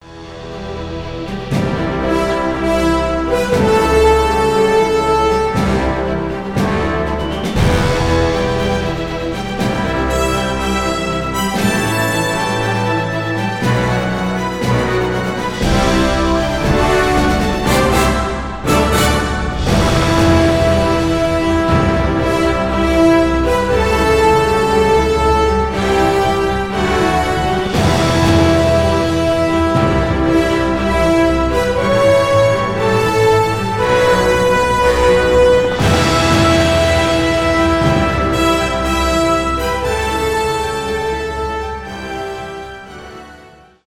инструментальные , эпичные
без слов
оркестр